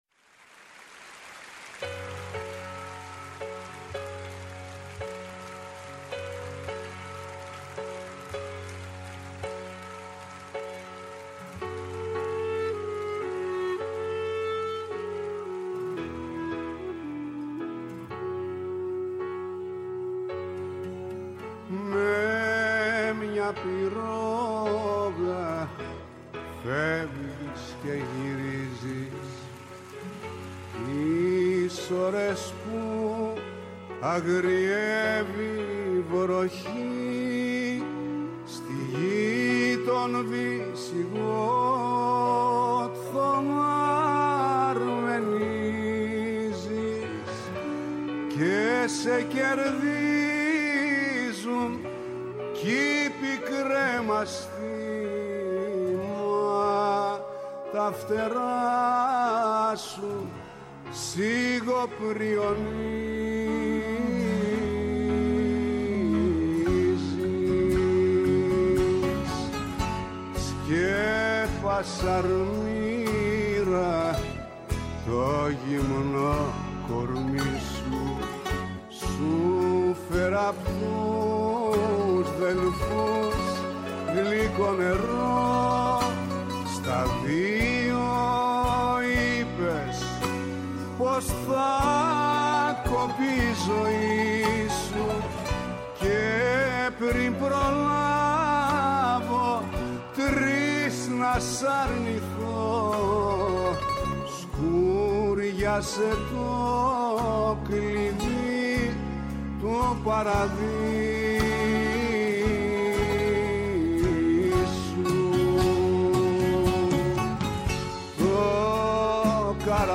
Απόψε καλεσμένος στο στούντιο